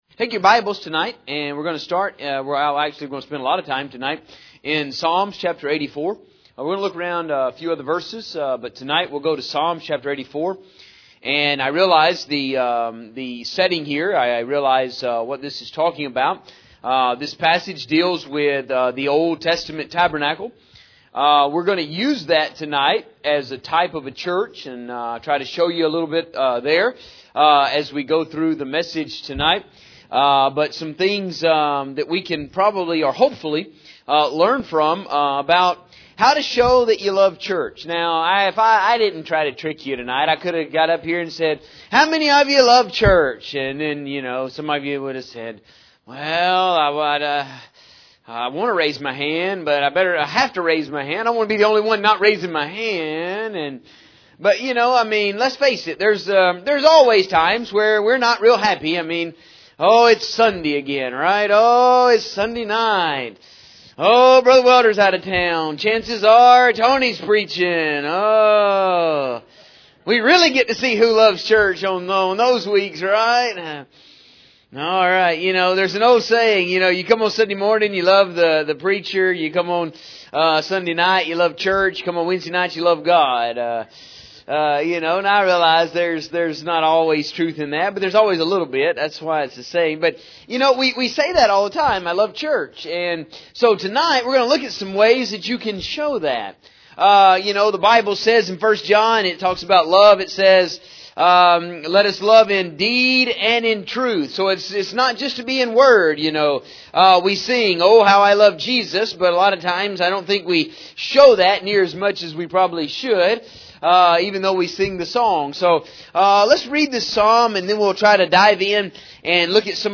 Now I realize that this is a reference to the Old Testament Tabernacle, but for this sermon we will use this as a type of the church.